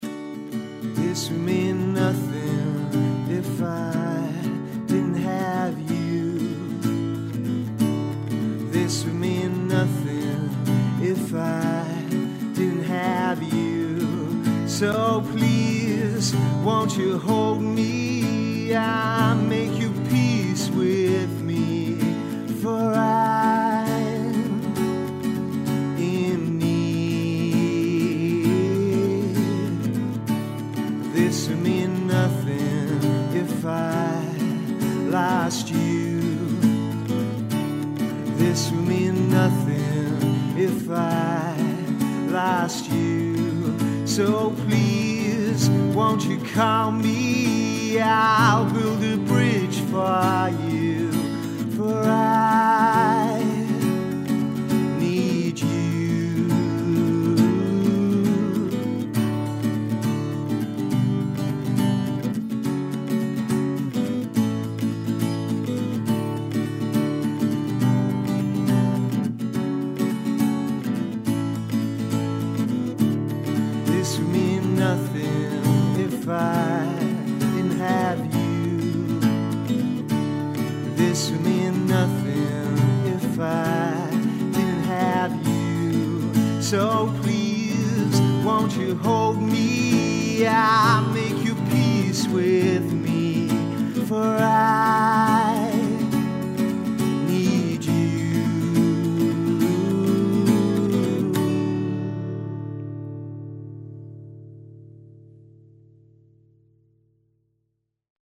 acoustic bonus track